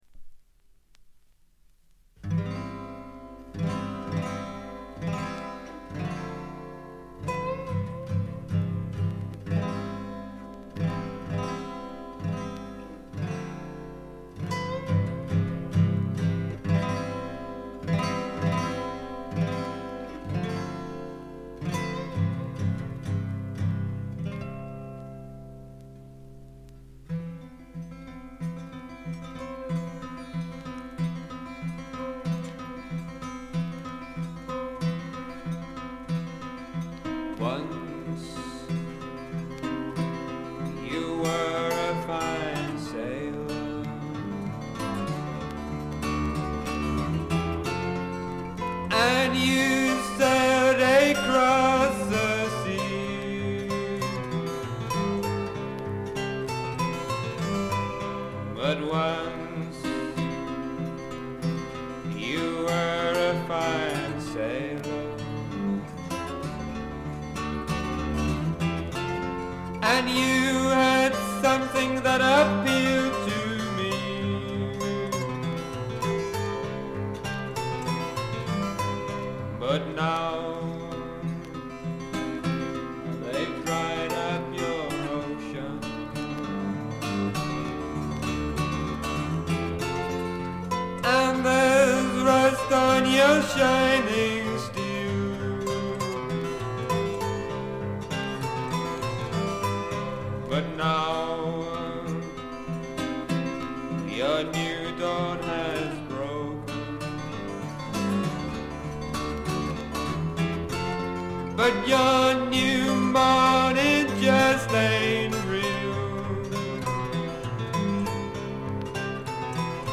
わずかなノイズ感のみ。
ラグ、ブルースを下地にしながらも超英国的なフォークを聴かせてくれるずばり名盤であります。
英国のコンテンポラリーフォーク／アシッドフォーク基本盤。
試聴曲は現品からの取り込み音源です。
Vocals, Guitar
Bongos [Bongoes]